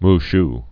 (m sh)